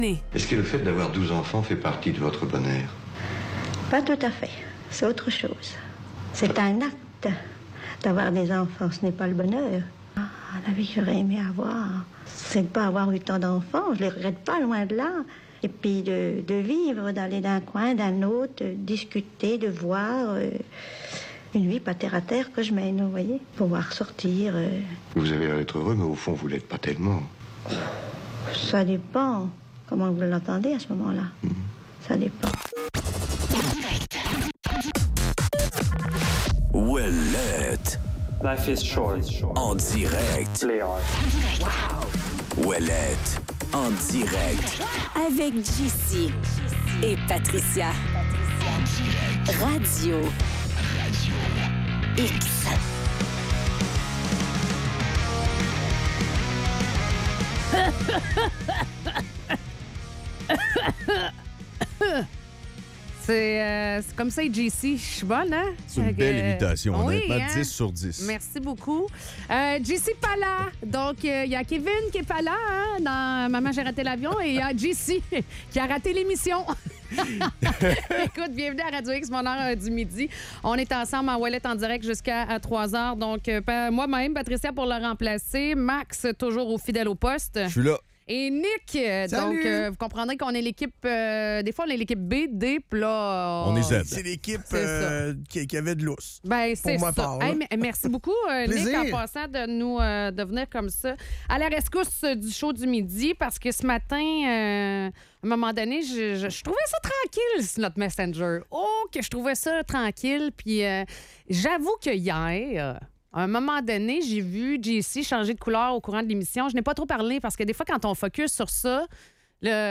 La Ville de Québec se prépare à affronter une tempête de verglas qui pourrait causer de nombreuses pannes d'électricité. Les animateurs partagent des conseils pratiques pour se préparer à ces conditions extrêmes, tout en discutant des enjeux liés au déneigement et des taxes sur l'essence.